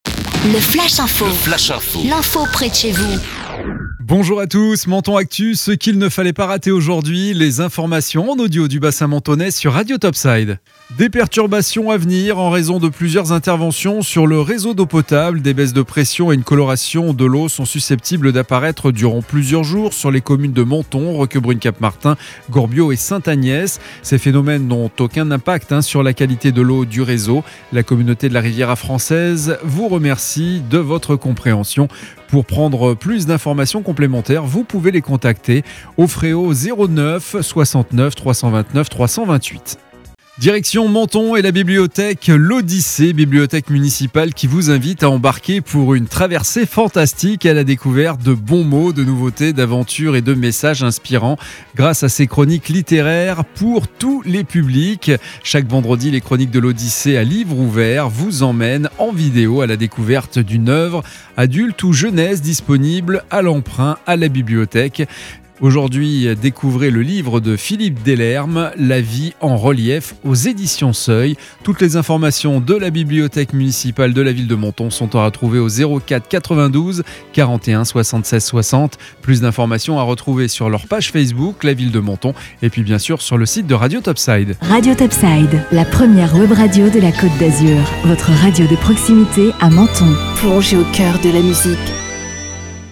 Menton Actu - Le flash info du samedi 8 mai 2021